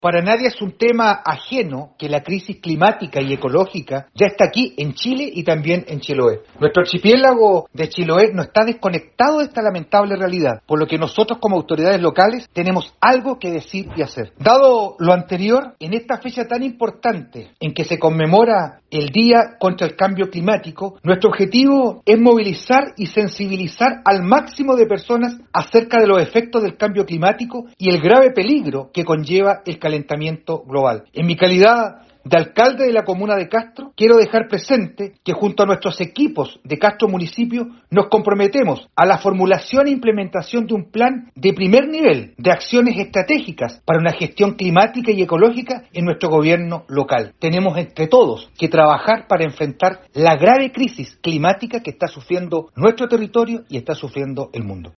Al respecto el edil castreño, señaló que para nadie es un tema ajeno que la crisis climática y ecológica ya está en Chile y también en Chiloé: